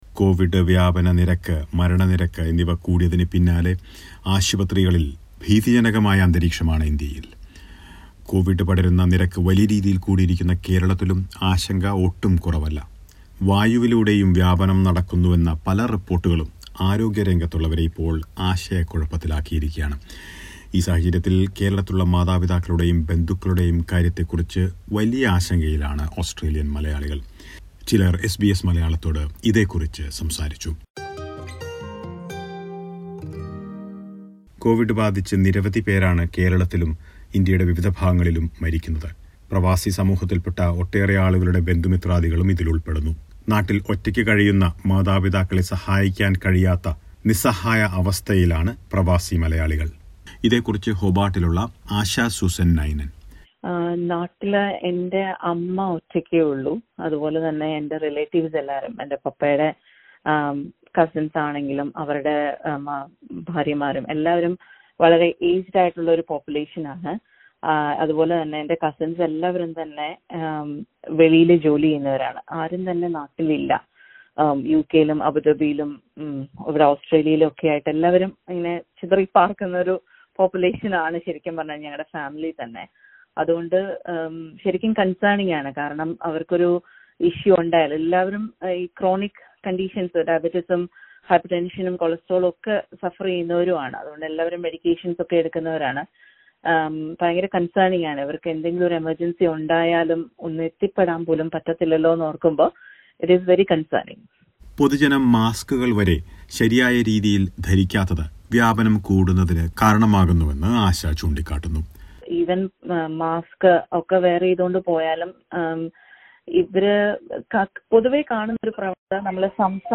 ഇന്ത്യയിലെ ഗുരുതരമായ കൊവിഡ് സാഹചര്യത്തിൽ മാതാപിതാക്കളുടെയും ബന്ധുക്കളുടെയും അടുക്കൽ എത്തിച്ചേരാൻ കഴിയാത്ത വേവലാതിയിലാണ് പ്രവാസി സമൂഹം. ചില ഓസ്‌ട്രേലിയൻ മലയാളികൾ എസ് ബി എസ് മലയാളത്തോട് ആശങ്ക പങ്കുവച്ചു.